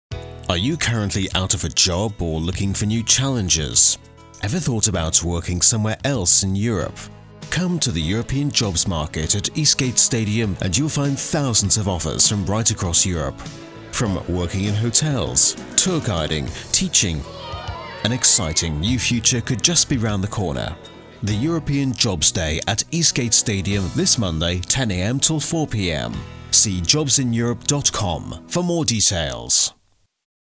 Englischer Sprecher
Native Speaker und Voiceover (UK) Englischer Sprecher mit Tonstudio.